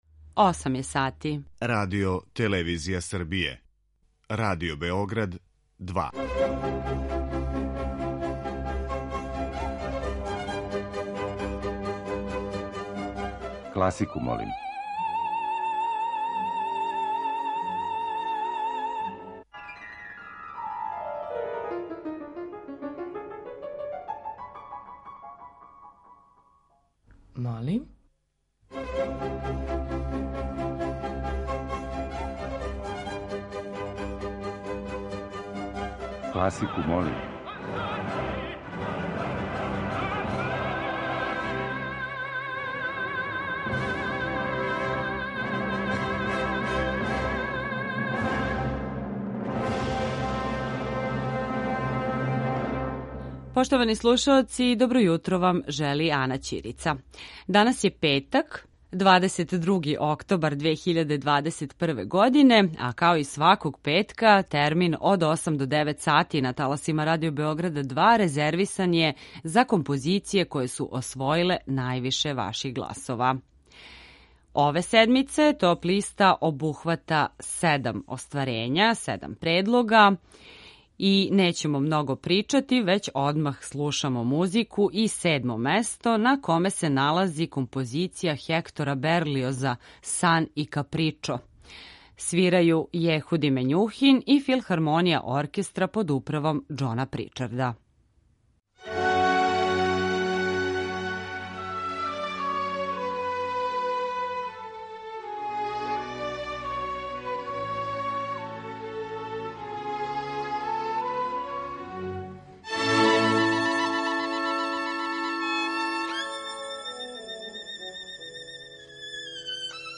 После сабирања гласова које смо примили од понедељка до четвртка, емитујемо композиције које су се највише допале слушаоцима и које се налазе на недељној топ-листи класичне музике Радио Београда 2.